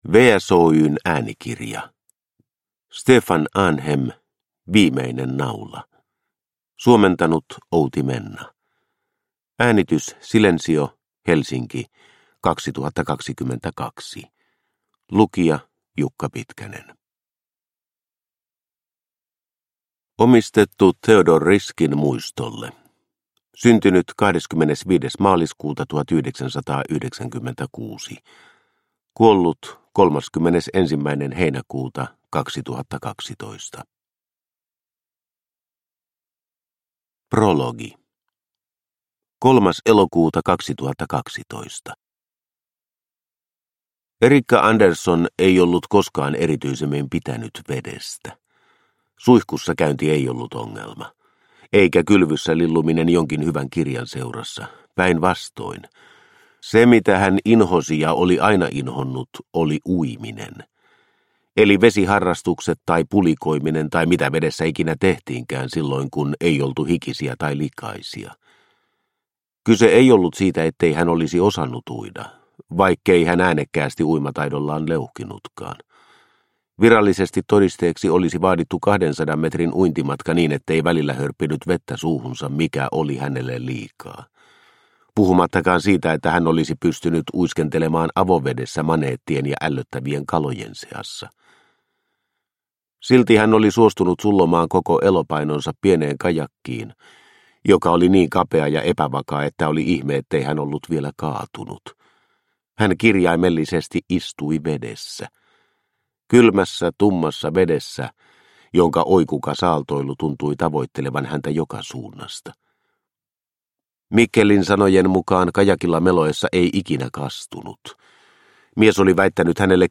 Viimeinen naula – Ljudbok – Laddas ner